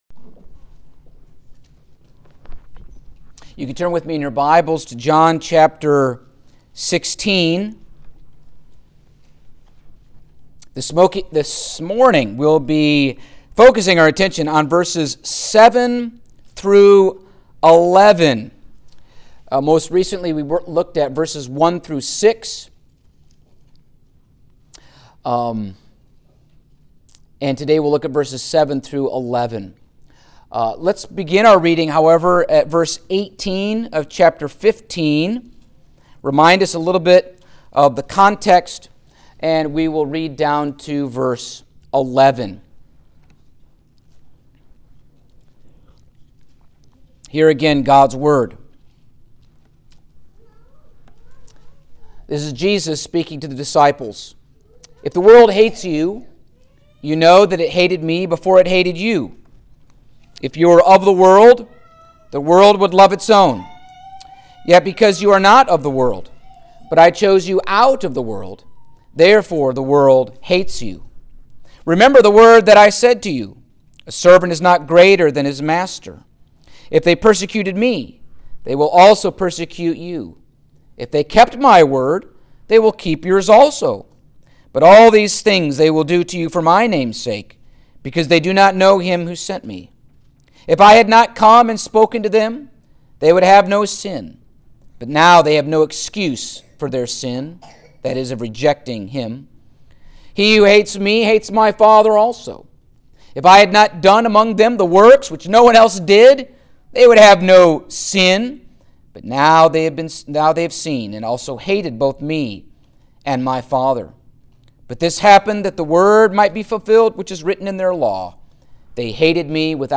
Passage: John 16:7-11 Service Type: Sunday Morning